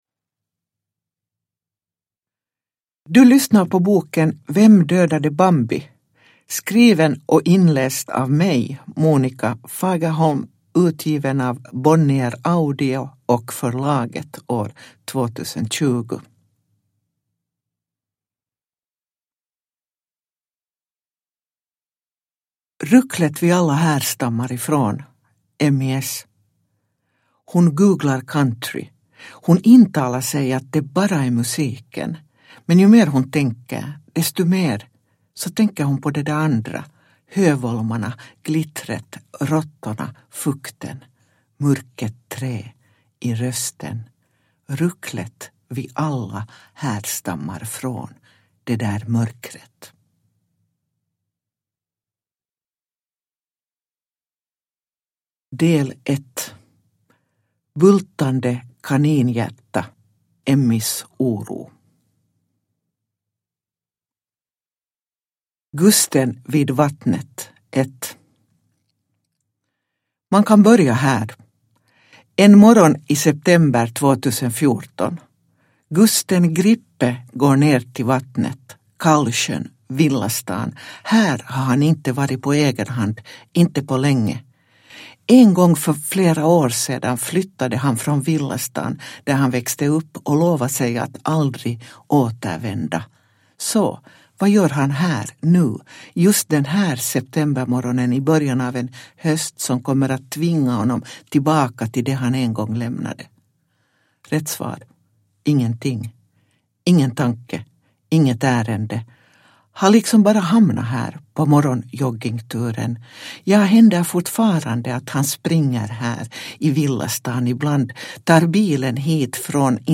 Uppläsare: Monika Fagerholm
Ljudbok